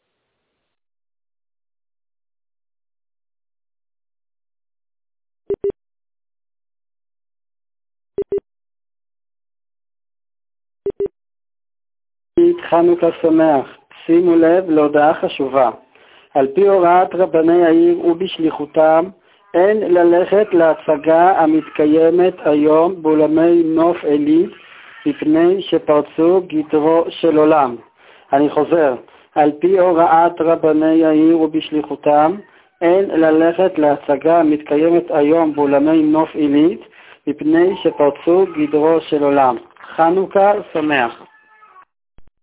רבני ק''ס שלחו הודעה טלפונית לא להשתתף בהצגה של חיים